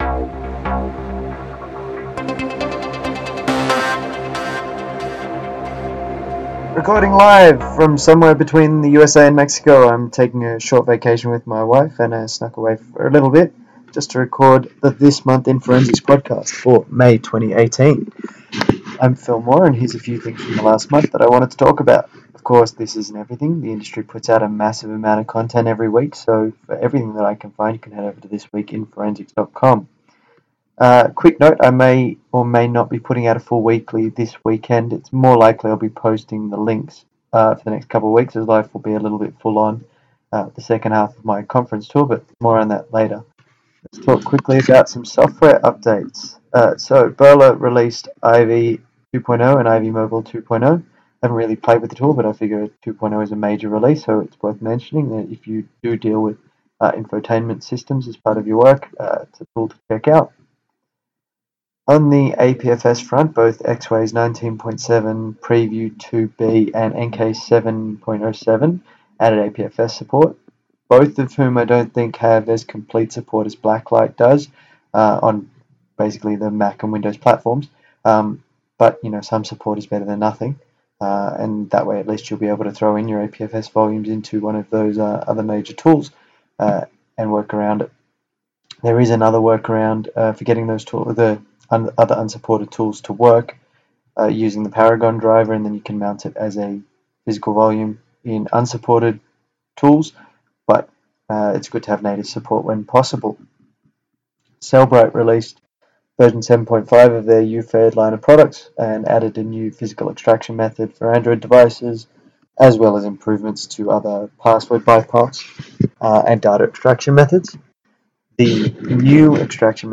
Sorry in advance for the audio quality, turns out recording on my laptop without a dedicated mic, on a cruise ship, results in bad audio.